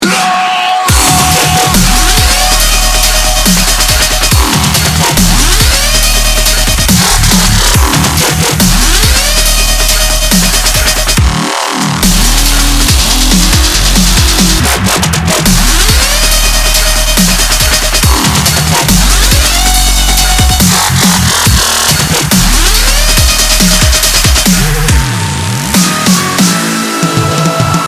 громкие